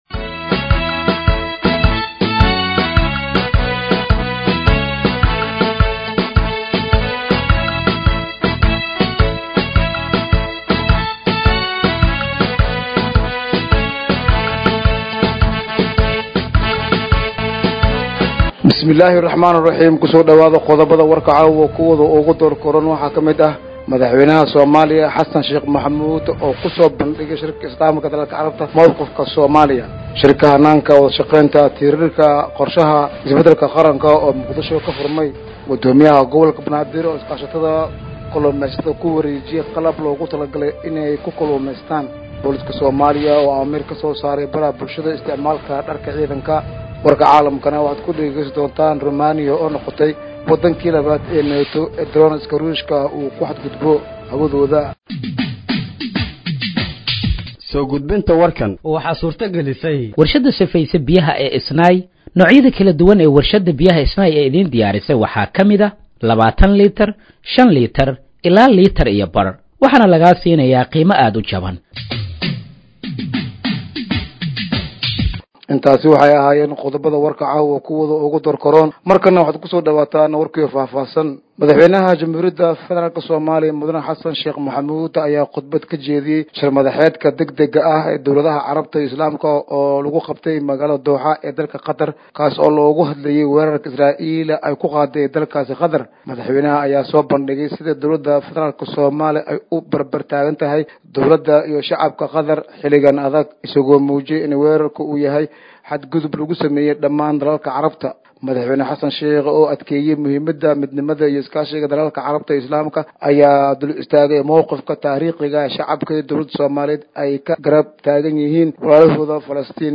Dhageeyso Warka Habeenimo ee Radiojowhar 15/09/2025